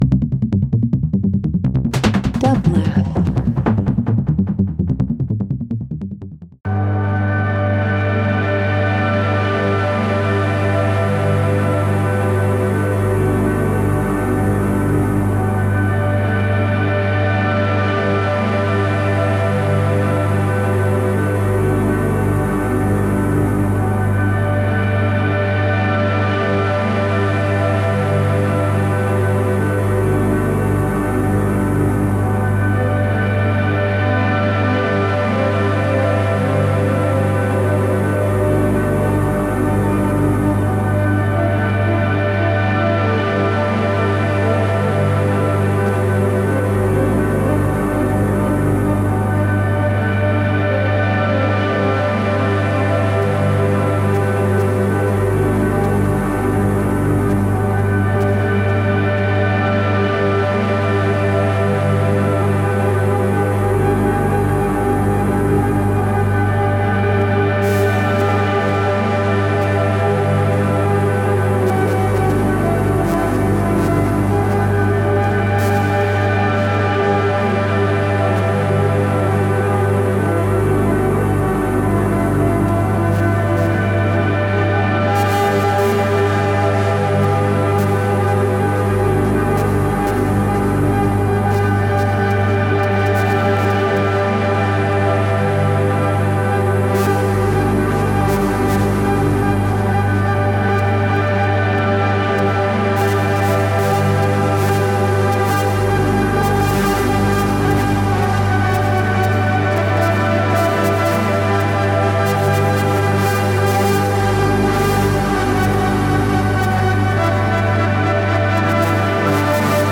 Ambient Electronic